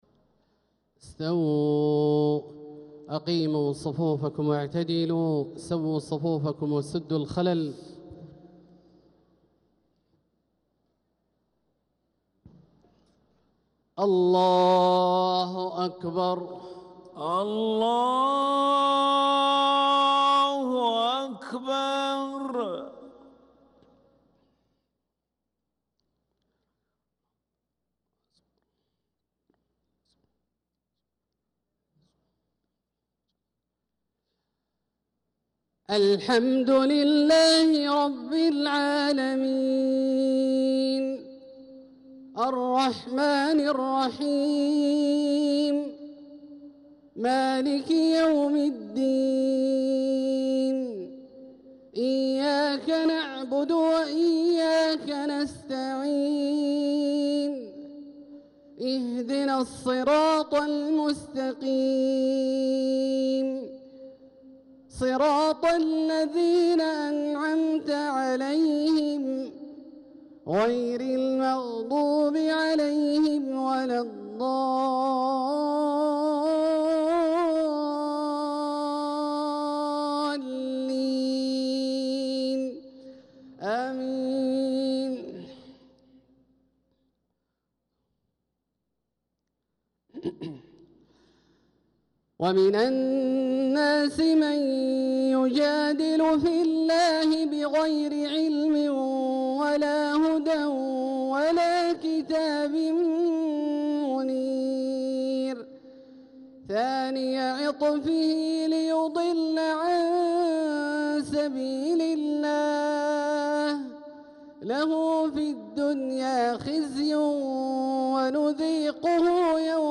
صلاة العشاء للقارئ عبدالله الجهني 7 ذو الحجة 1445 هـ
تِلَاوَات الْحَرَمَيْن .